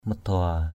/mə-d̪ʊa:/ (t.) ăn ở hai lòng, giả dối, không thủy chung = infidélité, perfidie; volage, inconstant. manuis madua mn&{C md&% kẻ ăn ở hai lòng, người giả dối.